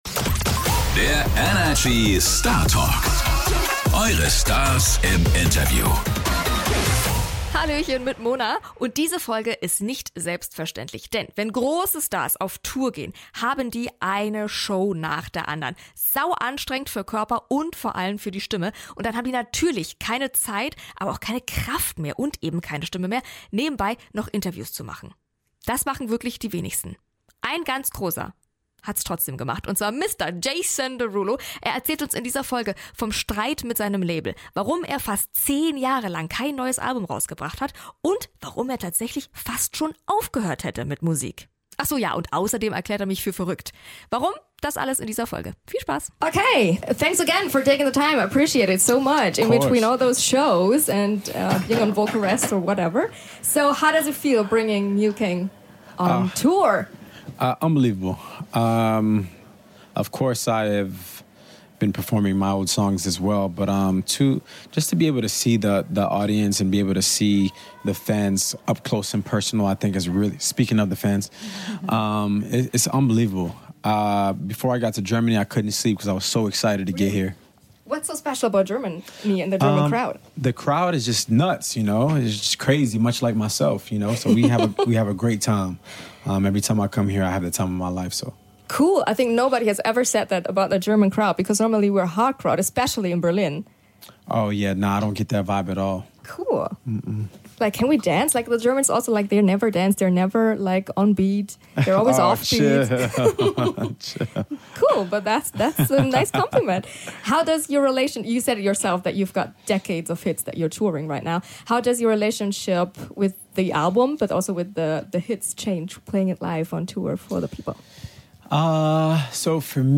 Dass sich Megastar Jason Derulo zwischen seinen Gigs Zeit für einen Talk nimmt, ist nämlich nicht selbstverständlich. Wie Jason sich auf seine Shows vorbereitet, wie es sich anfühlt, Jahre von erfolgreichen Hits in eine Tour zu verpacken und was er WIRKLICH über uns deutsche Fans denkt - das erfahrt ihr in diesem Startalk.